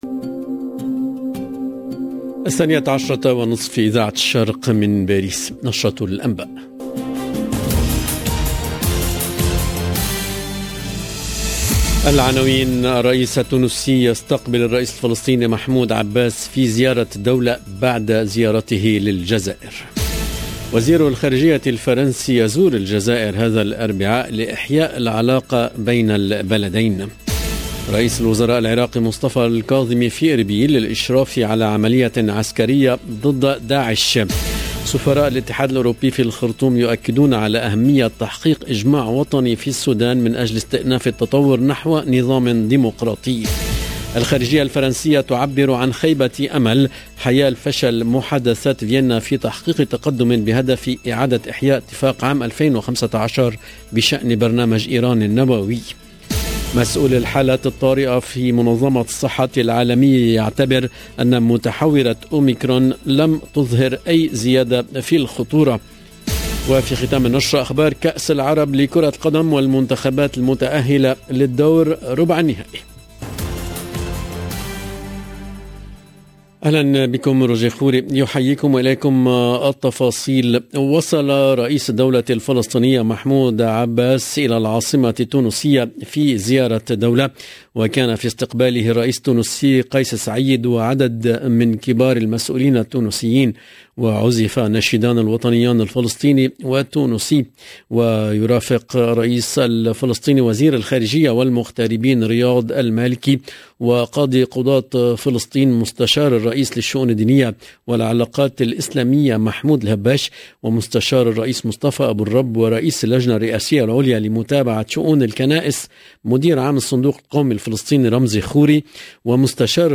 LE JOURNAL EN LANGUE ARABE DE MIDI 30 DU 8/12/21